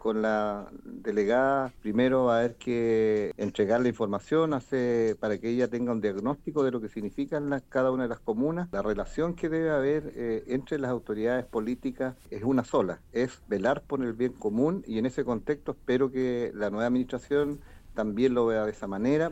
Mientras que el alcalde de Lanco, el socialista Juan Rocha, aseguró que entregará información de la comuna a la futura autoridad, para así garantizar que se consiga el bien común de los vecinos.
alcalde-lanco-nueva-delegada.mp3